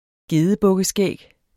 Udtale [ ˈgeːðəbɔgəˌsgεˀg ]